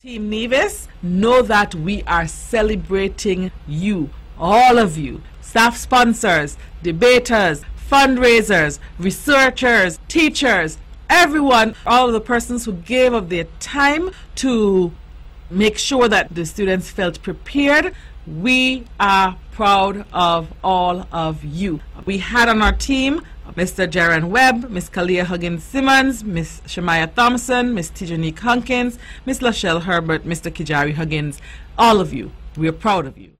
Permanent Secretary in the Ministry of Education, Youth, et. al., Ms. Zahnela Claxton, gave this comment: